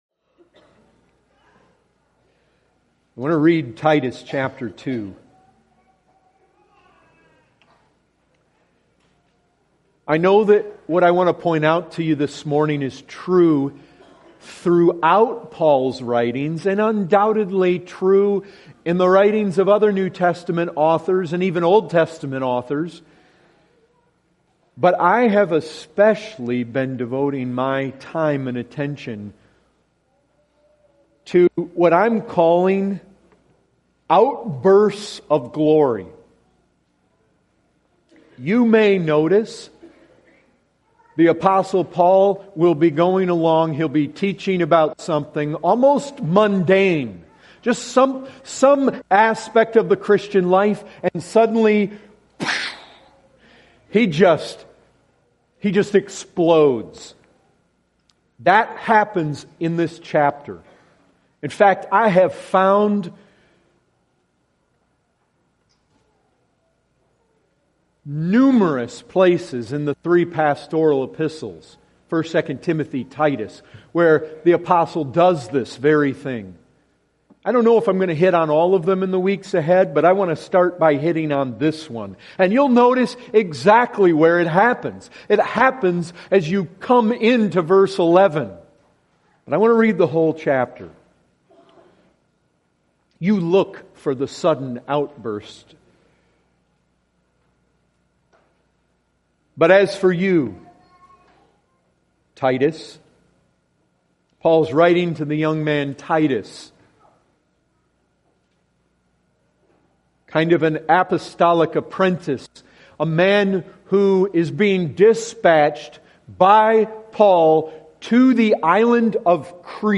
Full Sermons